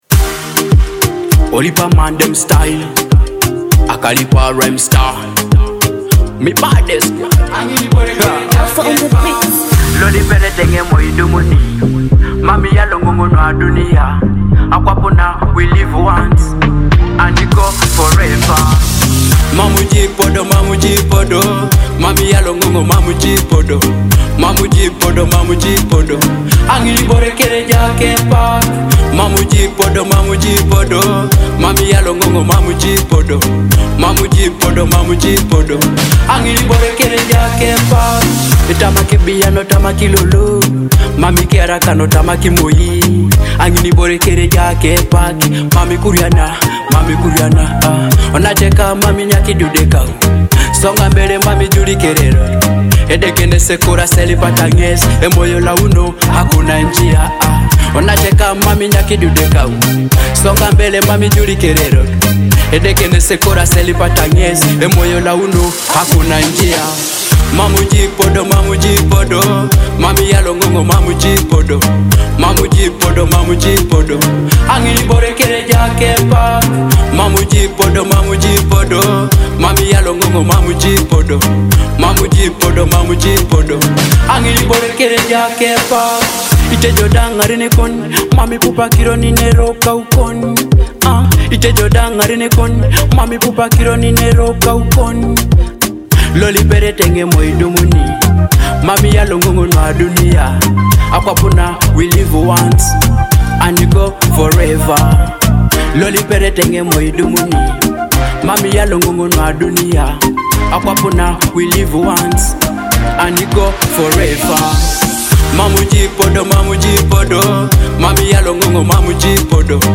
dynamic Afrobeat–Dancehall fusion
With its energetic grooves and uplifting message